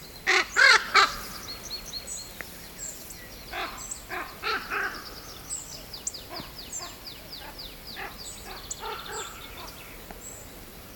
ворон, Corvus corax
Skaits50 - 60